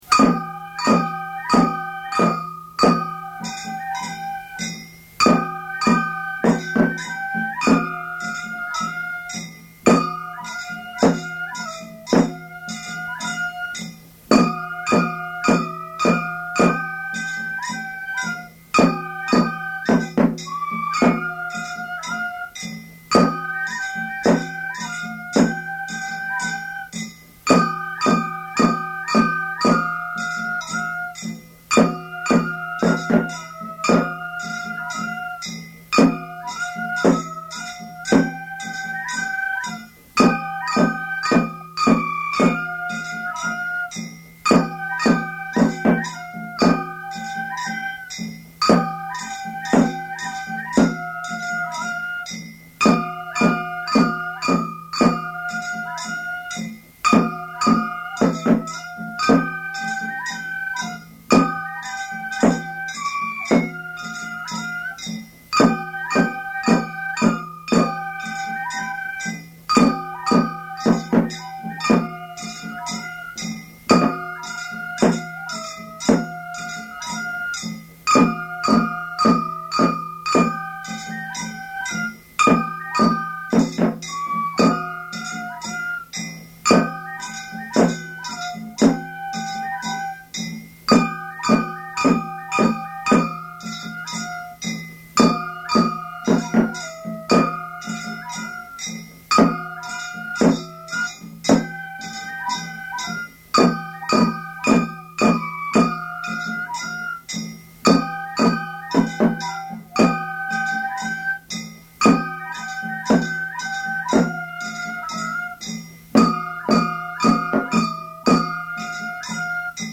宵山 山の巡行の前夜、宵山の晩に行う華やかな曲。
昭和62年11月1日　京都太秦　井進録音スタジオ